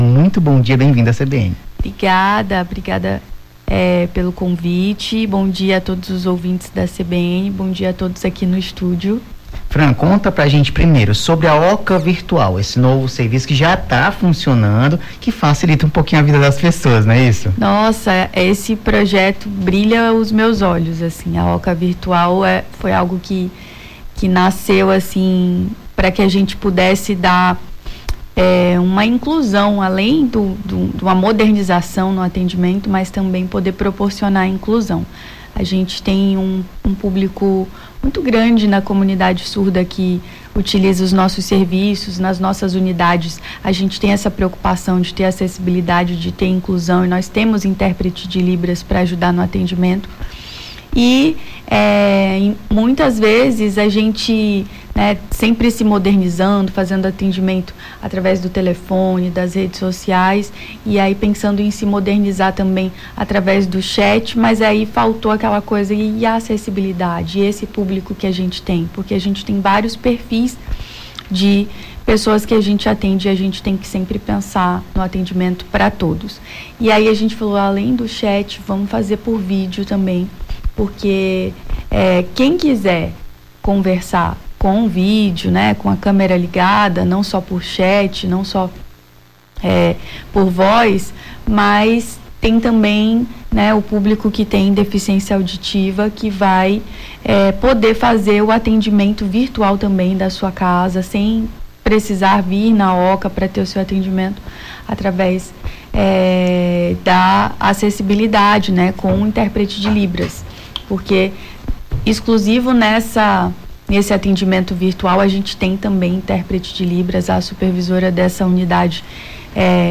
Nome do Artista - CENSURA - ENTREVISTA (OCA VIRTUAL) 23-05-23.mp3